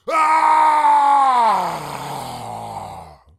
8 bits Elements
Zombie Voices Demo
zombie_attack_3.wav